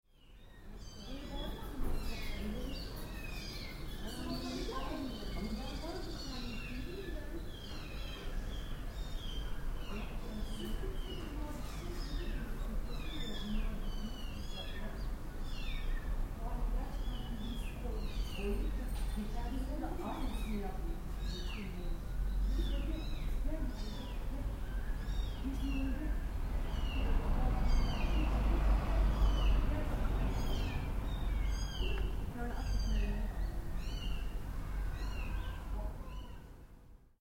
Kittens!
at home, Thursday afternoon
Squealing kittens, tiny tiny meaows. Some neighbours were present, wondering what should be done with them.
kissut.mp3